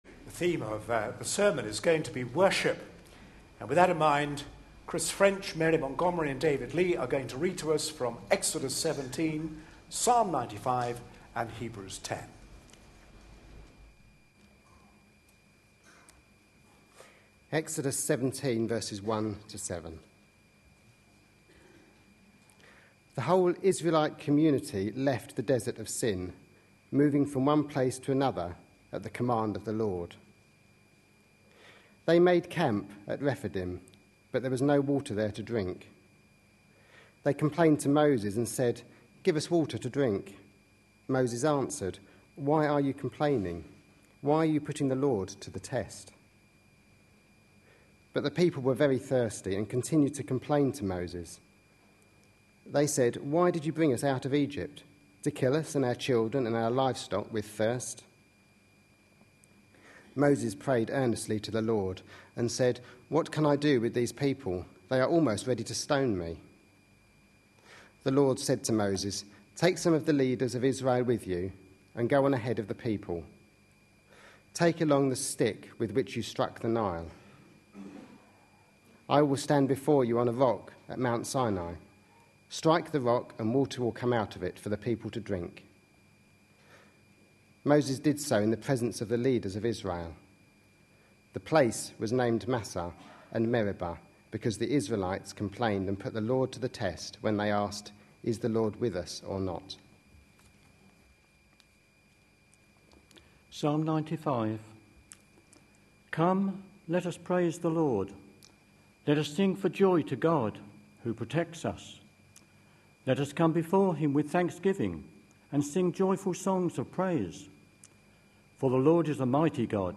A sermon preached on 27th January, 2013.